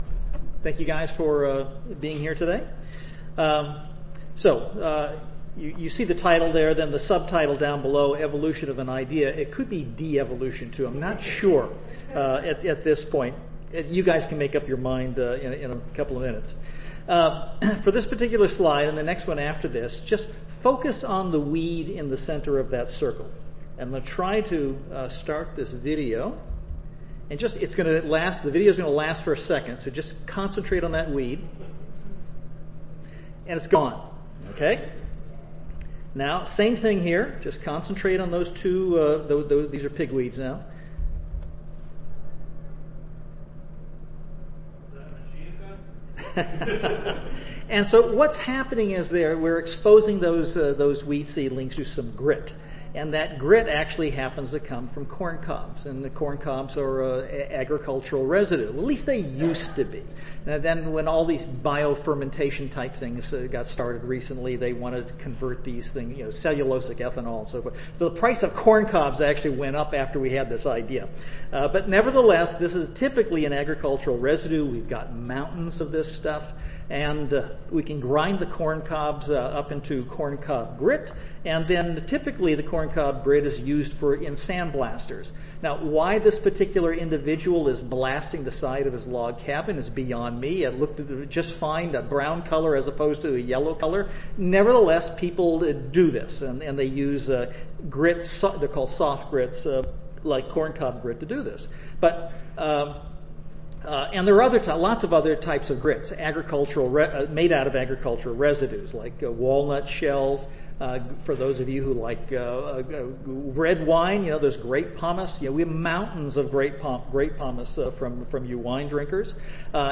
See more from this Division: ASA Section: Agronomic Production Systems See more from this Session: Symposium--Engineering Solutions and New Machines for Organic Agriculture